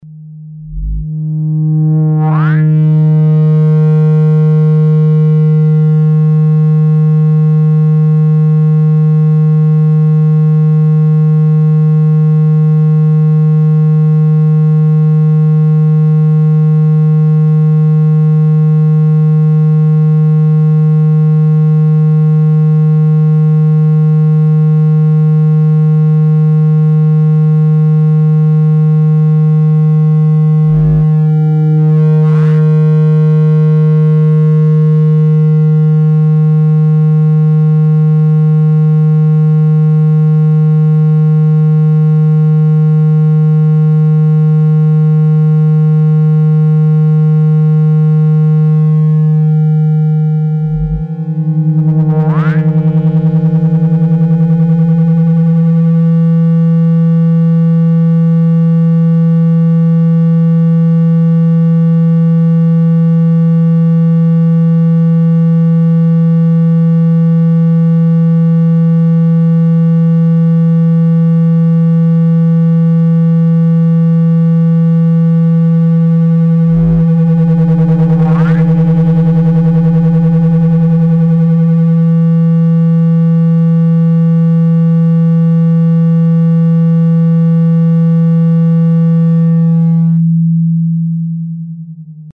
Звуки удаления воды
Подборка включает различные частоты и тональности, оптимальные для очистки наушников, смартфонов и ноутбуков.